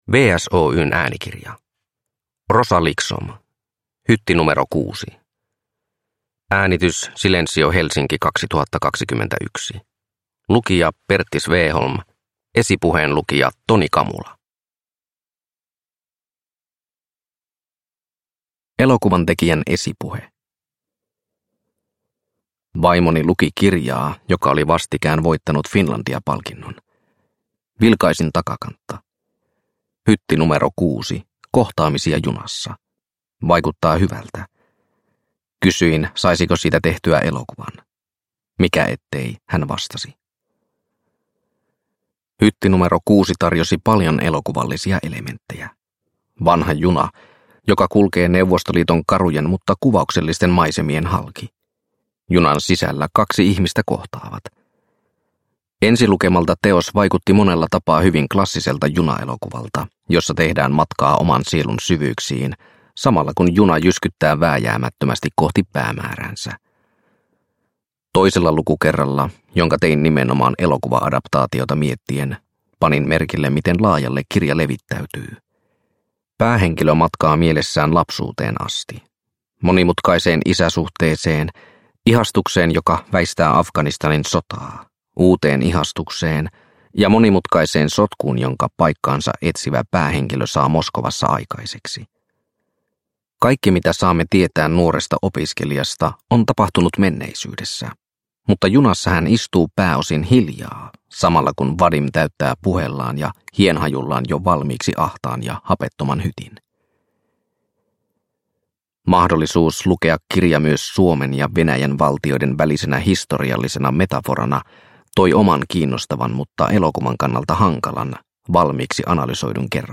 Hytti nro 6 – Ljudbok – Laddas ner